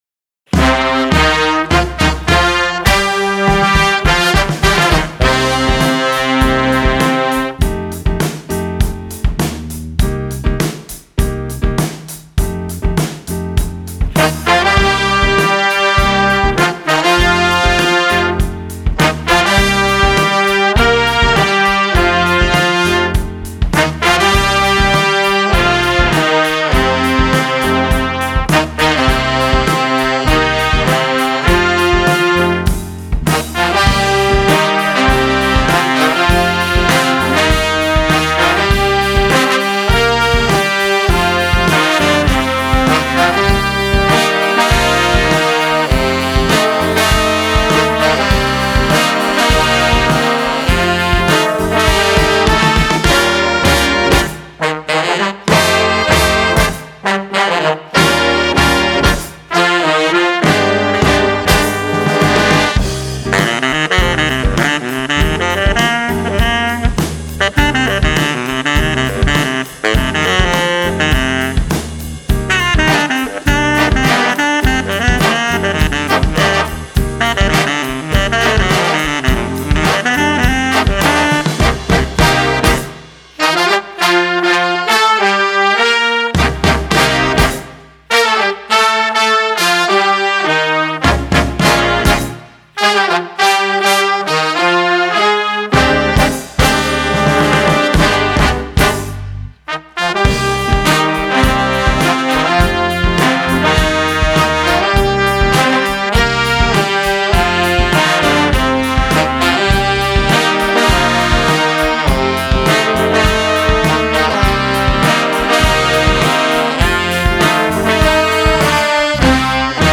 Voicing: Flex Jazz